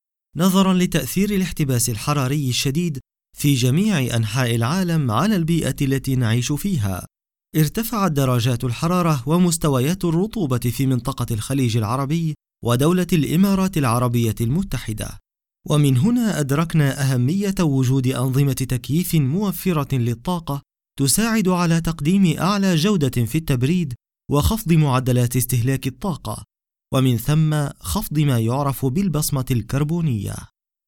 Native standard Arabic voice, believable, narrative, and warm
Sprechprobe: Industrie (Muttersprache):